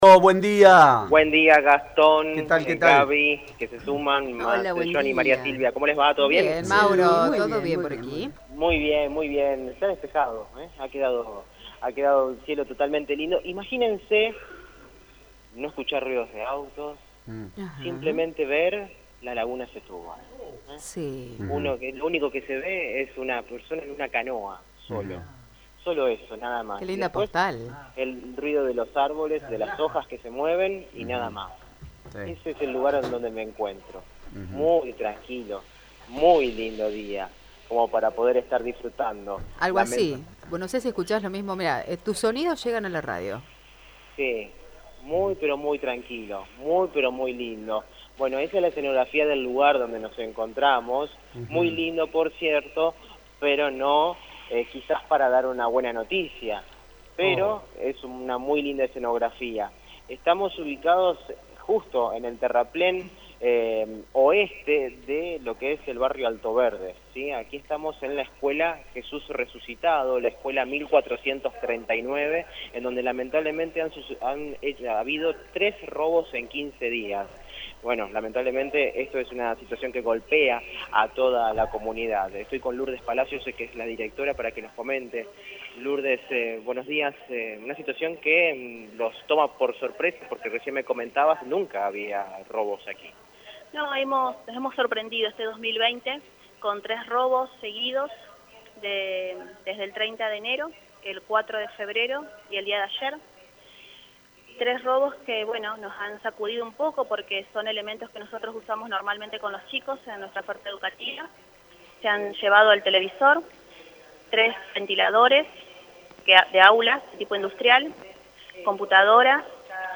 Móvil de Radio EME: